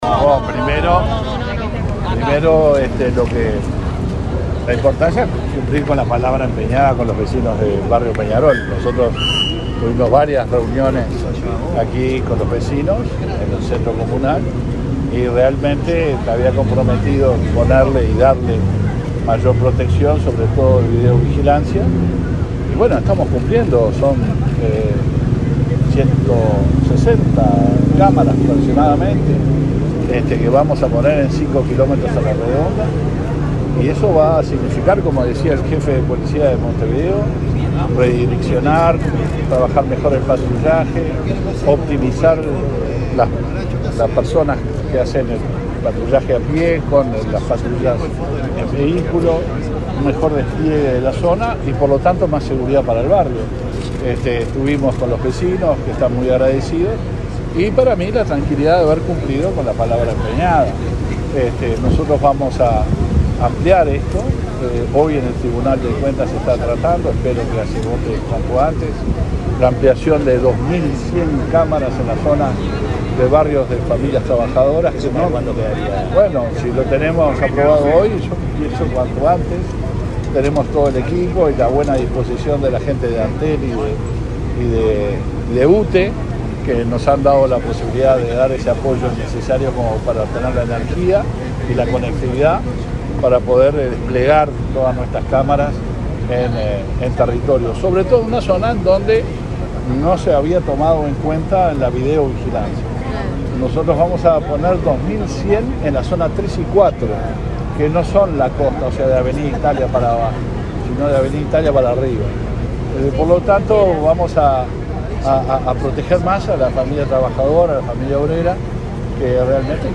Declaraciones del ministro del Interior, Luis Alberto Heber
Este miércoles 28, el ministro del Interior, Luis Alberto Heber, participó en la inauguración de cámaras de videovigilancia en el barrio Peñarol, en
Luego dialogó con la prensa.